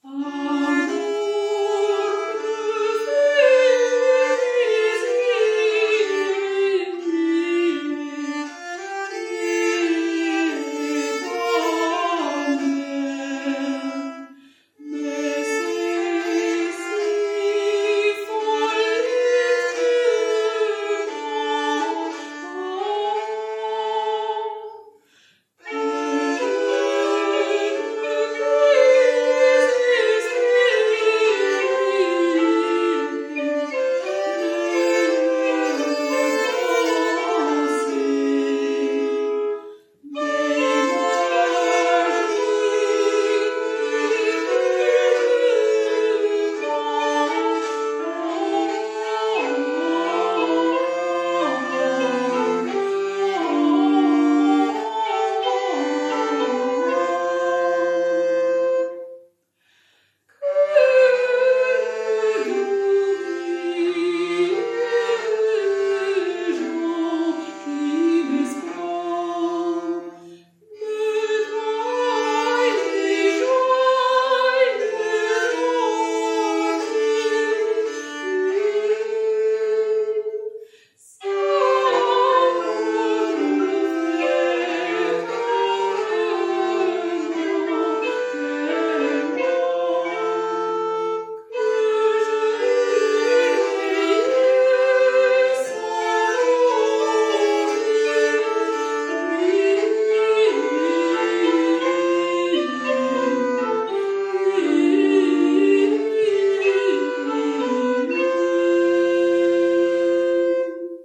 Frühe Musik:
Ensemblearbeit auf Burg Fürsteneck, Fortbildung zur Musik des Mittelalters